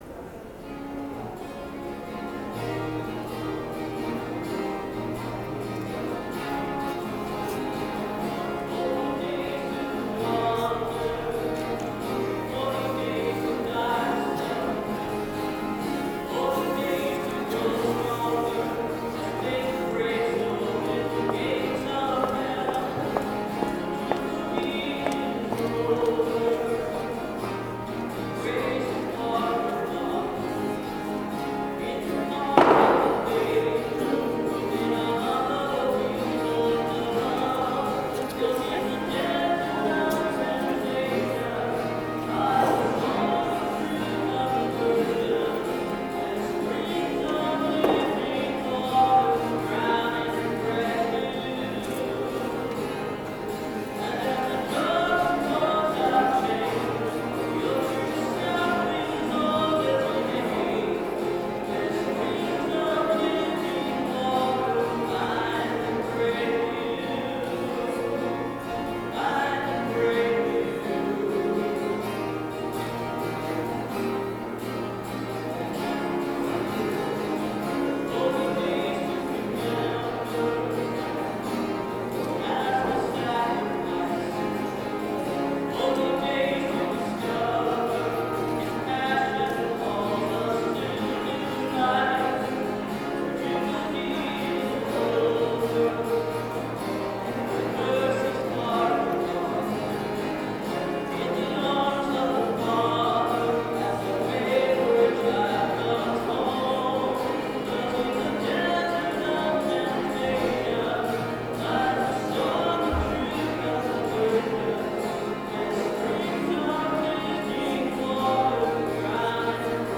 Music from the 10:30 Mass on the 1st Sunday in Lent, March 3, 2013:
(Note that the sound system was not turned on for this song)